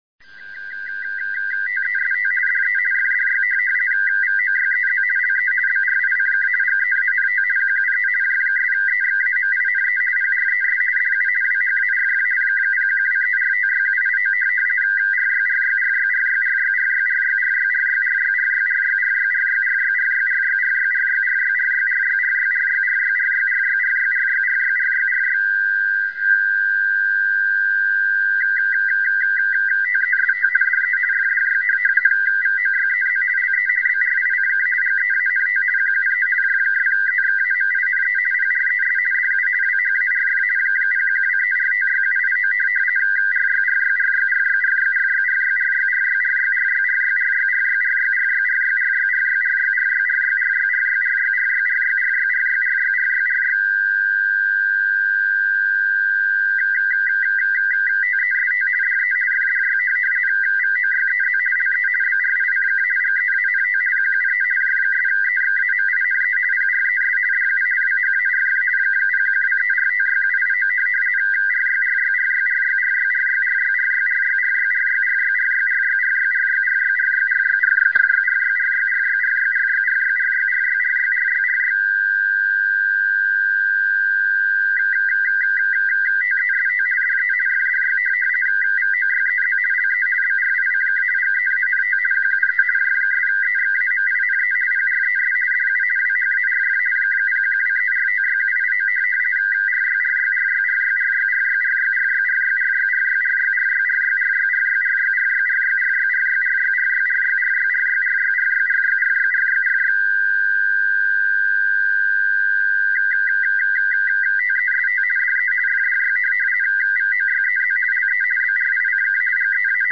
Und sofort erkenne ich auch, dass es sich um einfaches Fernschreiben handelt:
7855,5   2238   FDY   AF Orleans   F   RTTY 50   VOYEZ LE BRICK…